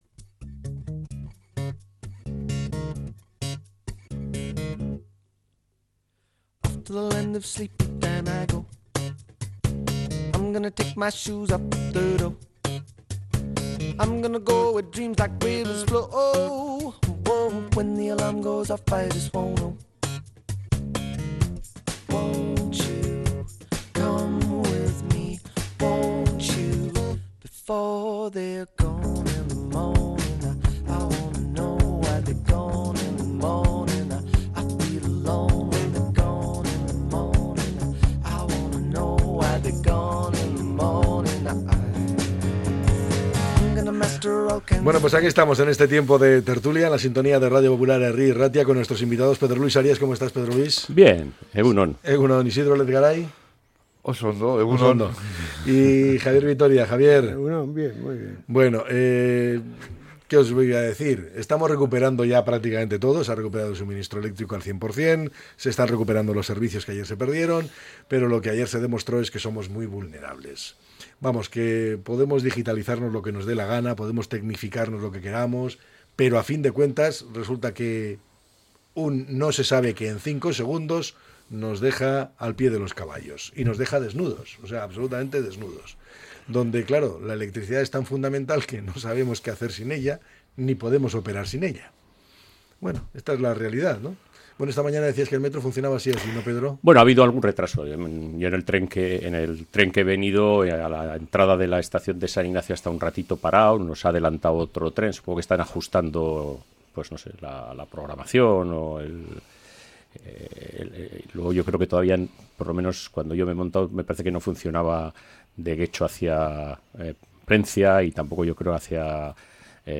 La tertulia 29-04-25.